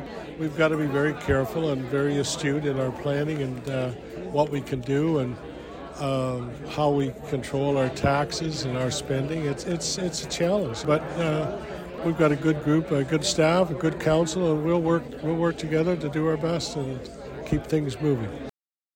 Quinte West Mayor Jim Harrison gave his State of the City Address to the Chamber of Commerce on Friday touching on some of the successes of 2022 and looked ahead to what’s to come this year.
The event was held at the Ramada in Trenton.